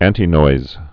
(ăntē-noiz, ăntī-)